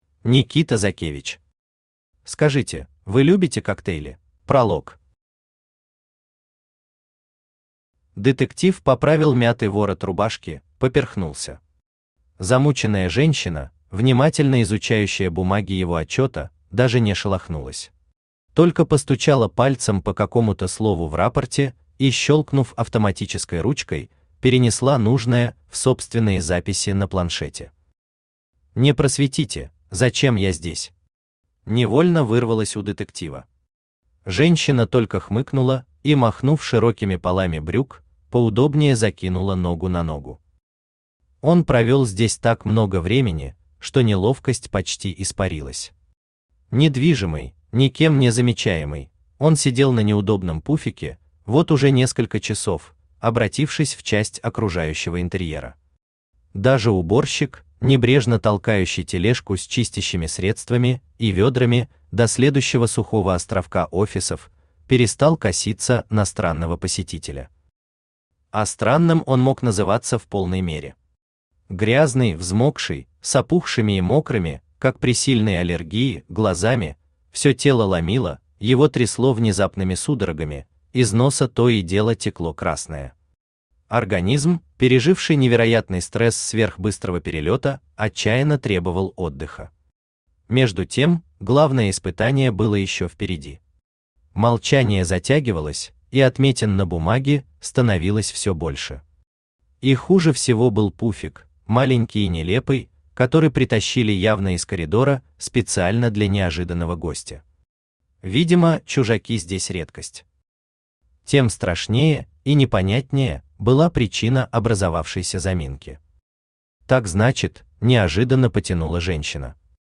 Автор Никита Закевич Читает аудиокнигу Авточтец ЛитРес.